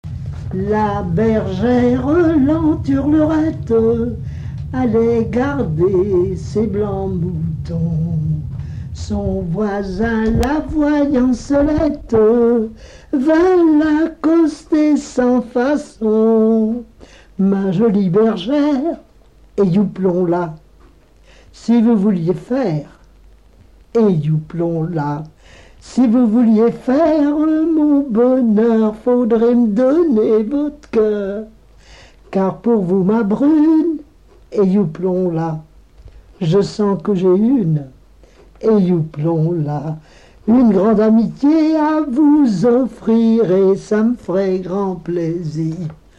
Montournais
Genre laisse
Pièce musicale inédite